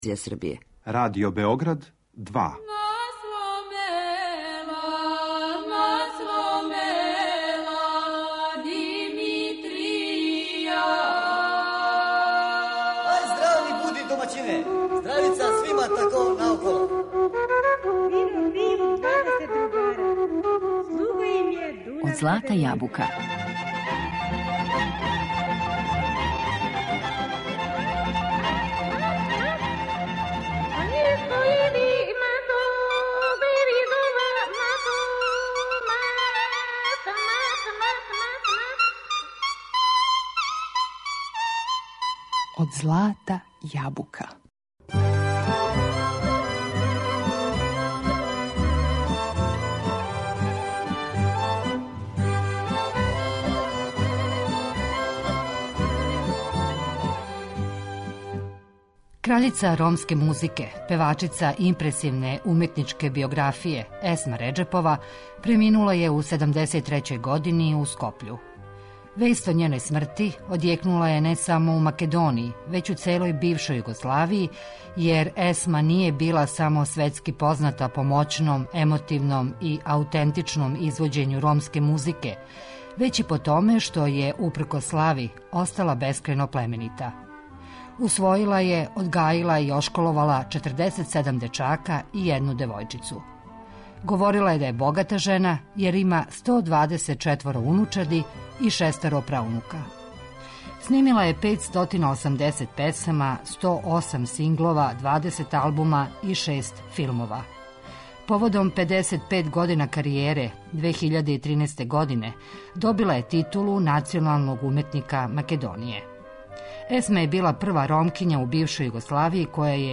У две емисије, у знак сећања на ову уметницу, поред песама које су обележиле њену каријеру, слушаћемо и документарни материјал који смо забележили 2003. године.